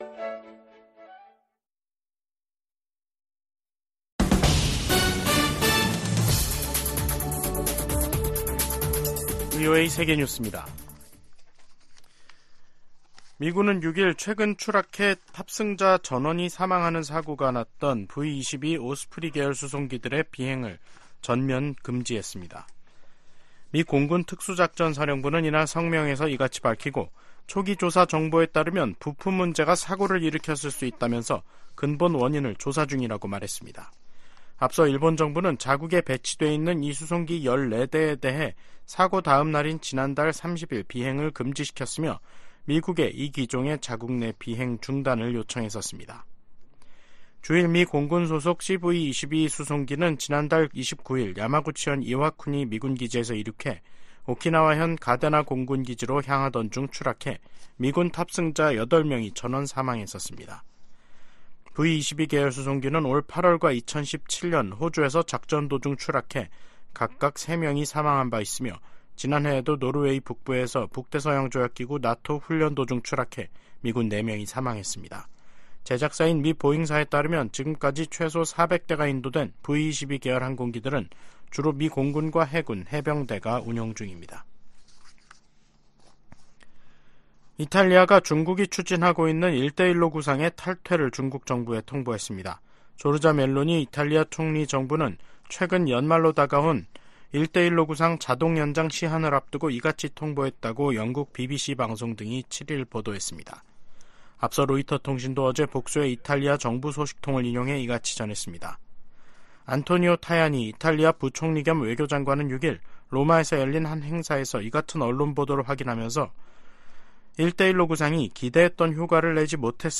VOA 한국어 간판 뉴스 프로그램 '뉴스 투데이', 2023년 12월 7일 3부 방송입니다. 한국 정부가 처음으로 김정은 북한 국무위원장의 딸 김주애 후계자 내정설에 관해 가능성 높다는 공식 판단을 내놨습니다. 미한일 안보 수장들이 오는 9일 서울에서 역내 안보 현안들을 집중 논의합니다. 자유를 향한 탈북민 가족들의 이야기를 그린 다큐 영화가 새해 초 공영방송을 통해 미국의 안방에 방영됩니다.